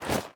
equip_leather6.ogg